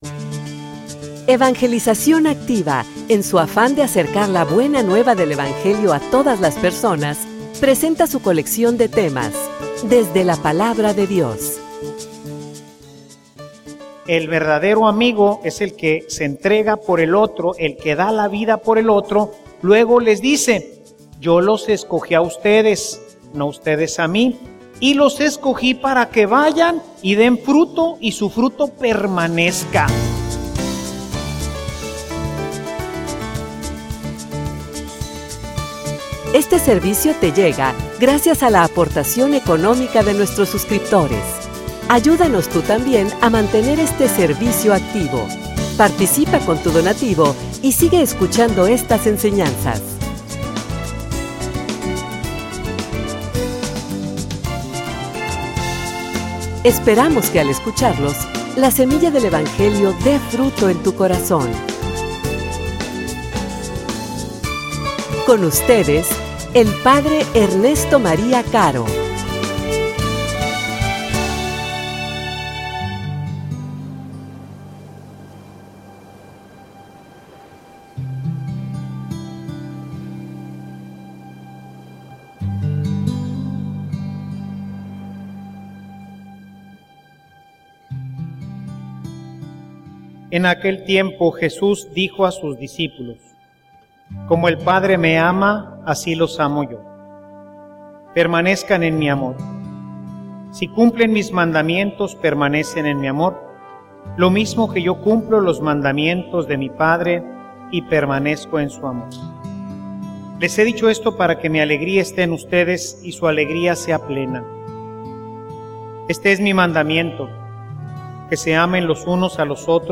homilia_Amigos_o_companeros.mp3